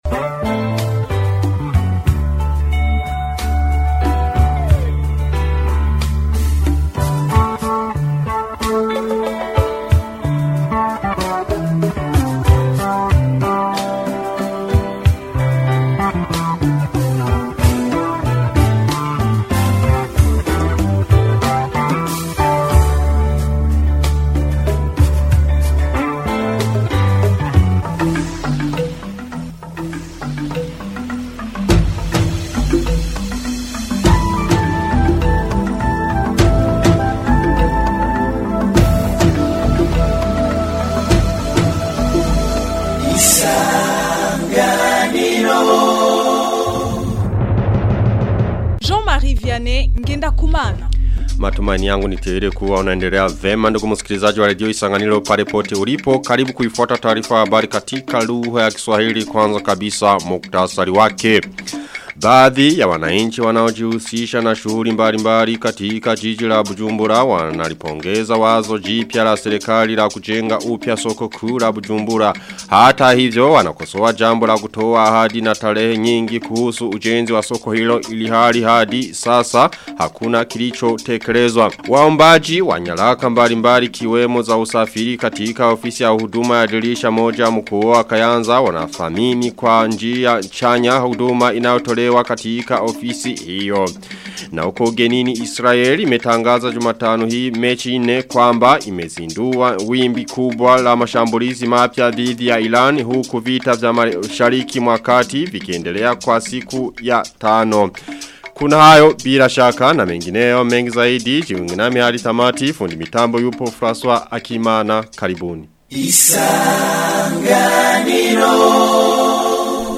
Taarifa ya habari ya tarehe 4 Marchi 2026